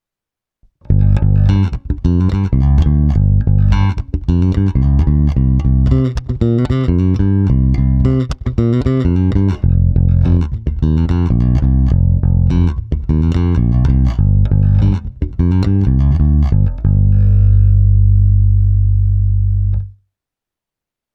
Nahrávku jsem prohnal multiefektem Hotone Ampero, ale v něm mám zapnutou prakticky jen drobnou ekvalizaci a hlavně kompresor.
Oba snímače - basy +50%, středy +50%, výšky +50%